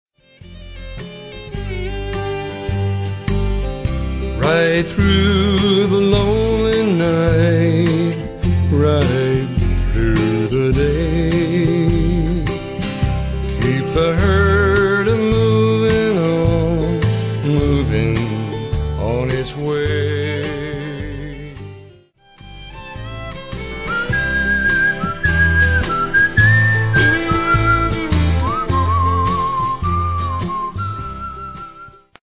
Recorded in Nashville in 2000
traditional cowboy favorites